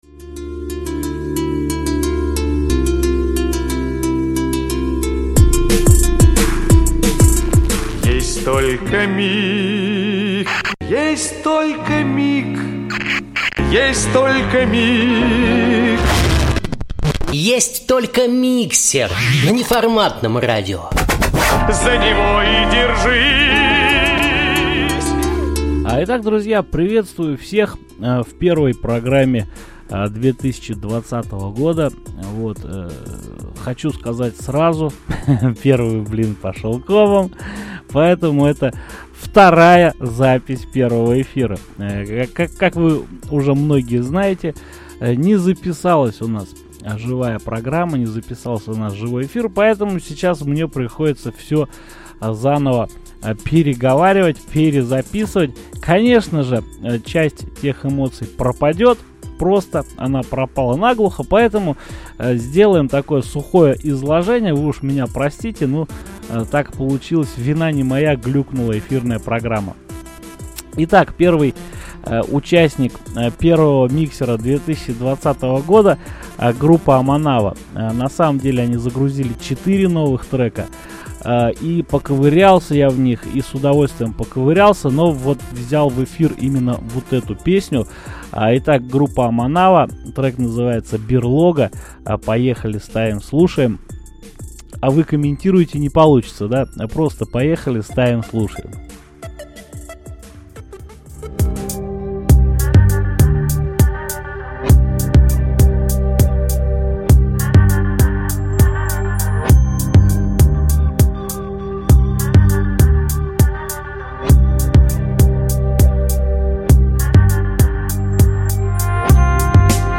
Из - за сбоя эфирной программы мы лишились записи эфира. Поэтому пришлось всё наговаривать заново.